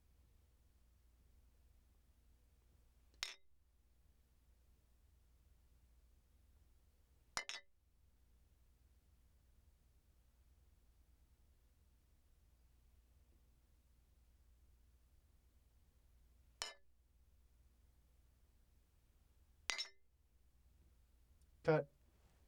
bottles clink nt 2
bottles clanging clinking ding glass hit hitting sound effect free sound royalty free Sound Effects